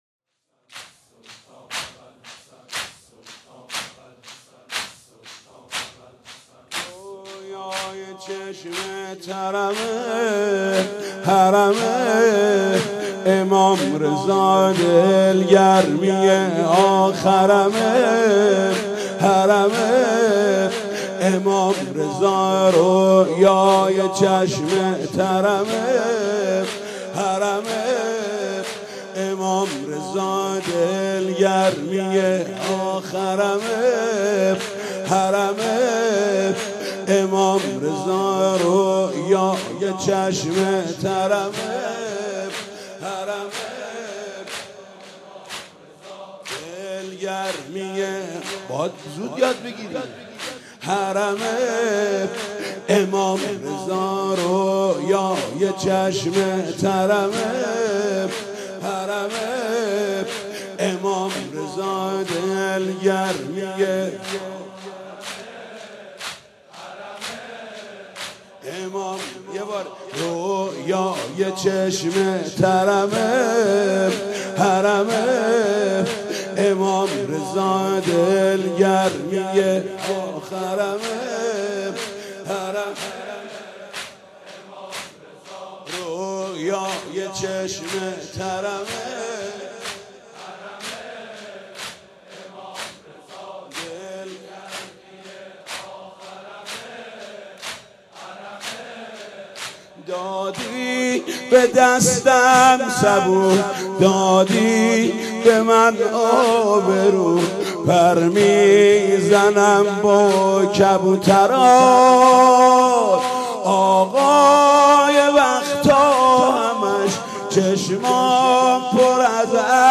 زمینه زیبا